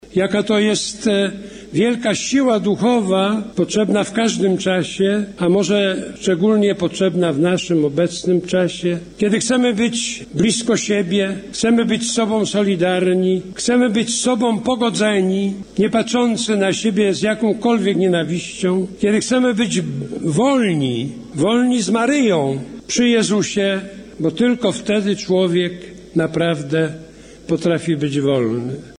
Chcemy być ze sobą solidarni, pogodzeni, nie patrzący na siebie z jakąkolwiek nienawiścią – podkreślił podczas  wieczornej mszy świętej w Częstochowie metropolita warszawski kardynał Kazimierz Nycz.
W czasie homilii kardynał Kazimierz Nycz wskazał na fenomen polskiego pielgrzymowania.